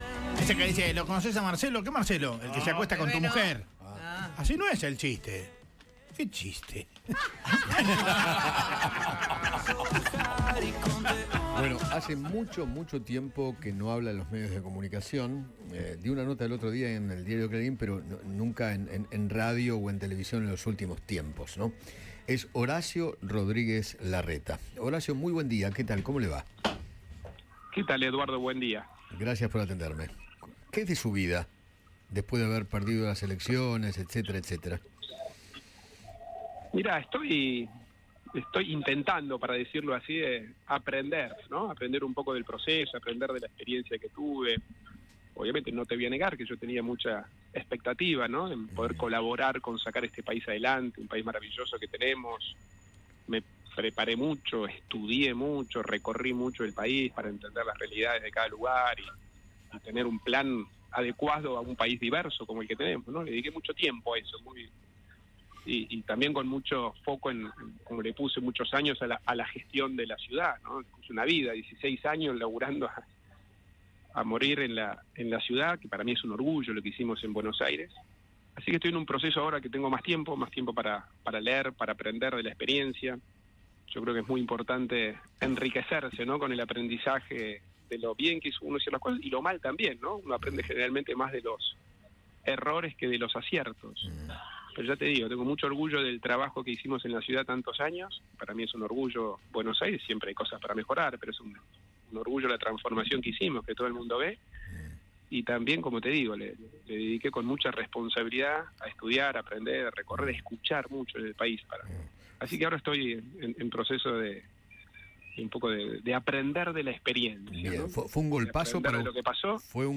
El ex jefe de Gobierno porteño Horacio Rodríguez Larreta habló con Eduardo Feinmann sobre el rol de Mauricio Macri en el nuevo gobierno y analizó la gestión de Javier Milei.